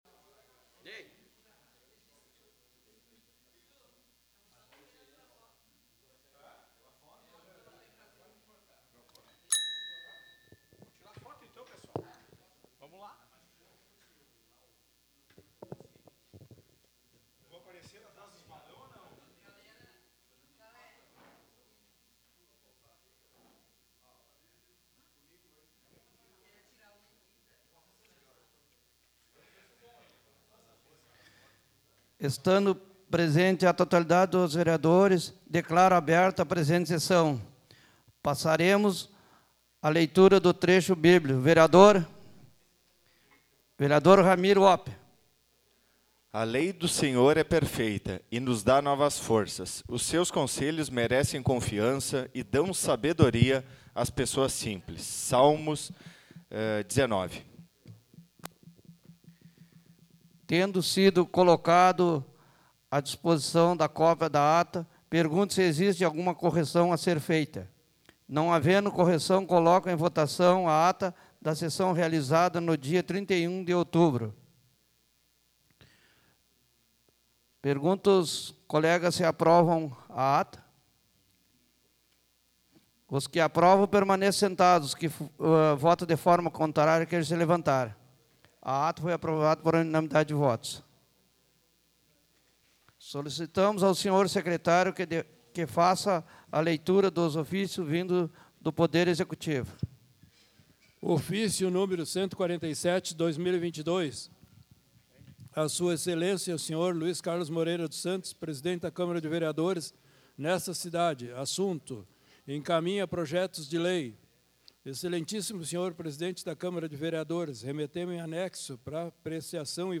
Áudio Sessão 07.11.2022 — Câmara de Vereadores